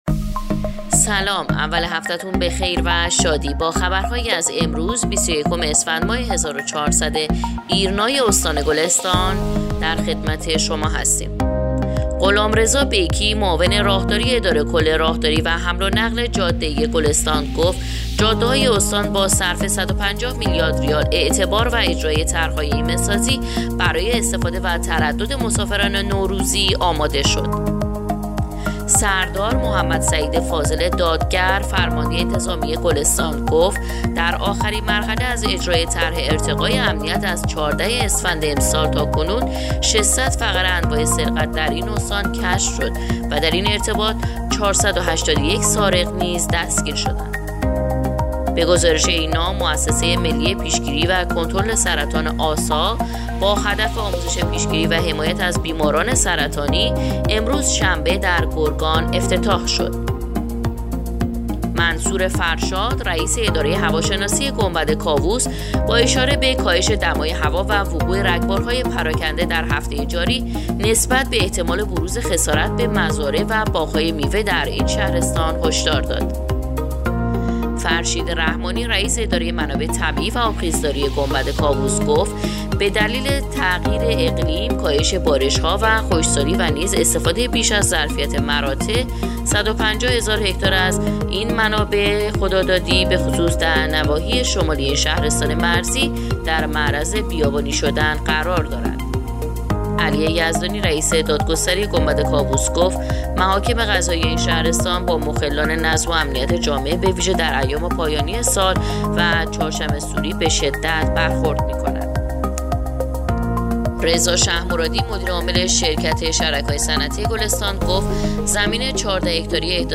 پادکست/ اخبار شبانگاهی بیست و یکم اسفندماه ایرنا گلستان